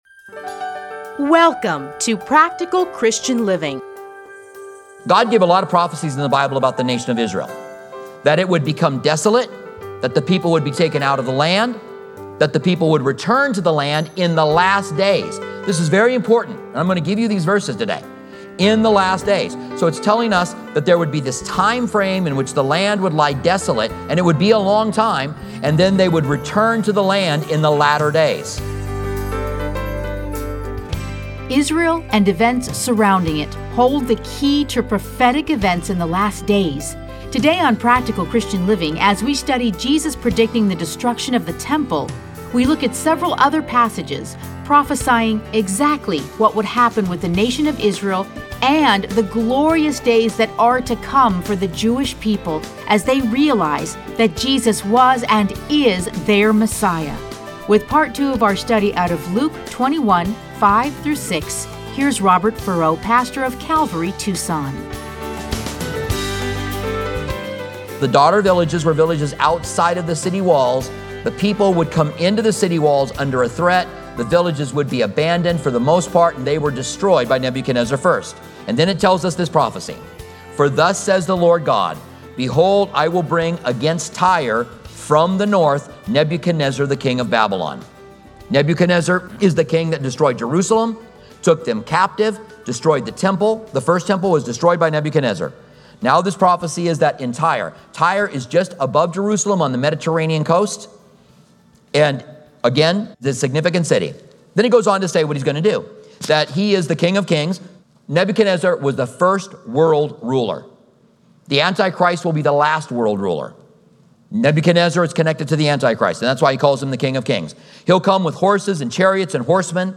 Listen to a teaching from Luke 21:5-6.